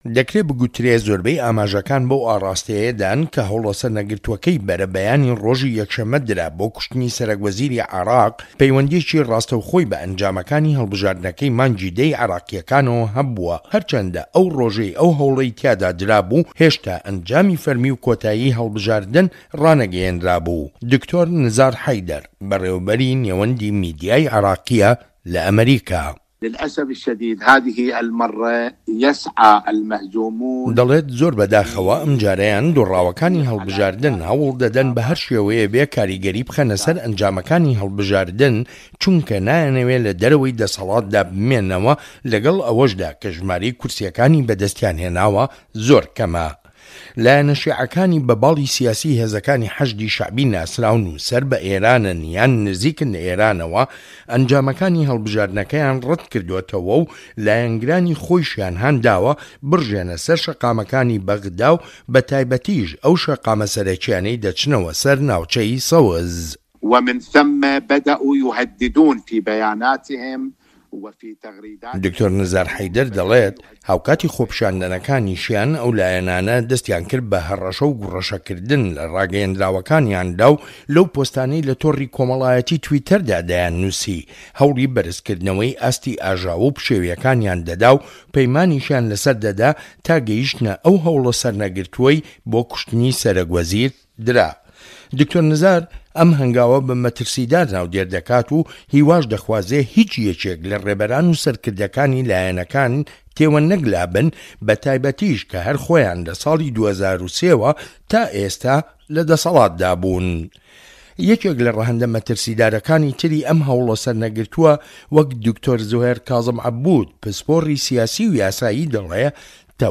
ڕاپۆرتی زەنگە ترسناکەکانی عێراق